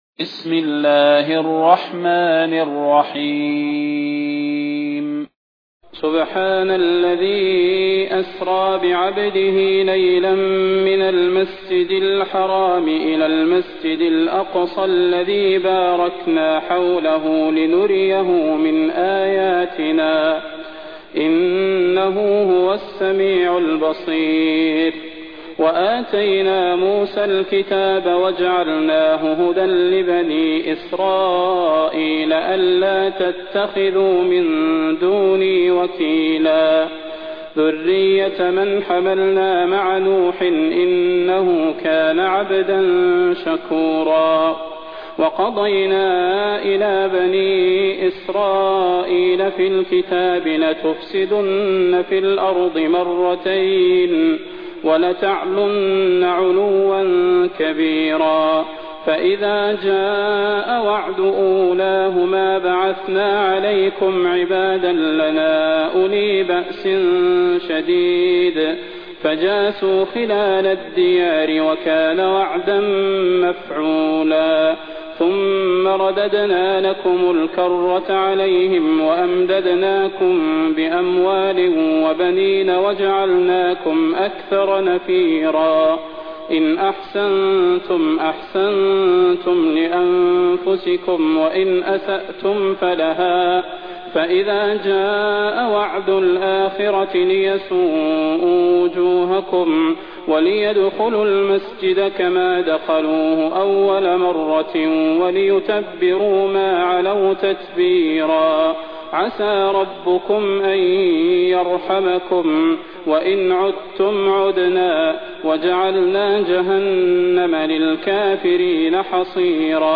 تلاوة سورة الإسراء بصوت الشيخ صلاح بن محمد البدير
المكان: المسجد النبوي الشيخ: فضيلة الشيخ د. صلاح بن محمد البدير فضيلة الشيخ د. صلاح بن محمد البدير سورة الإسراء